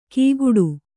♪ kīguḍu